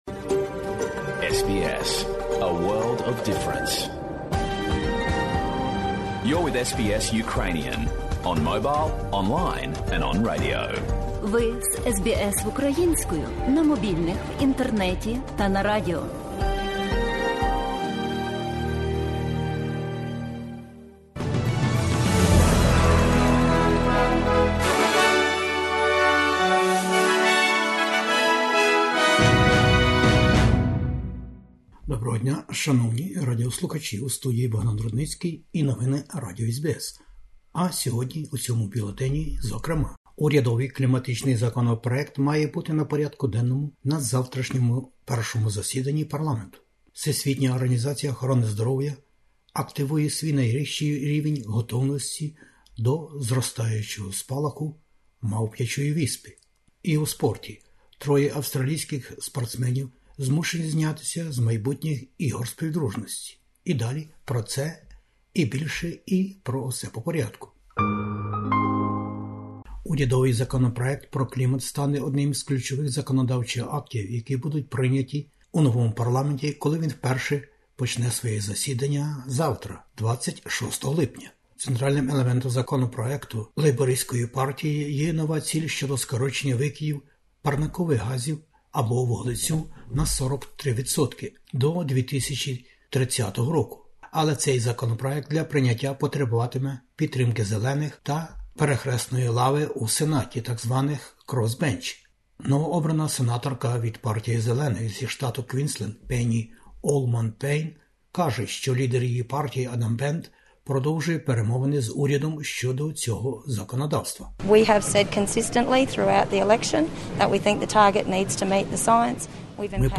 Бюлетень SBS новин українською. Завтра у перший день роботи парламенту Австралії розглядатимуть амбітний законопроєкт Лейбористської партії щодо зміни клімату.